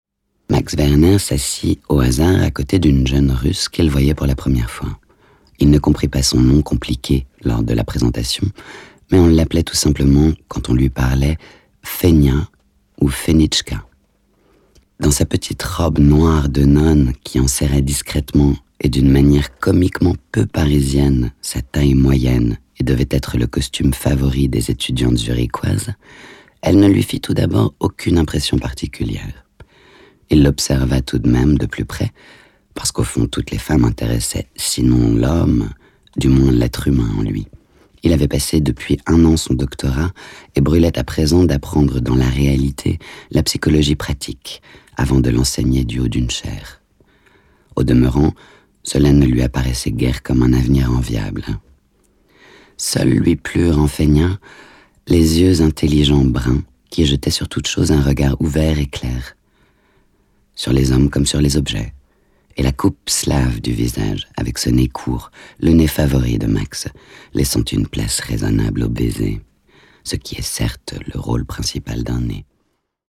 Anna Mouglalis a reçu le Grand Prix de littérature classique 2019 La Plume de Paon pour sa lecture de Fénichka suivi de Une longue dissipation de Lou Andreas-Salomé. Un livre audio de la collection La Bibliothèque des voix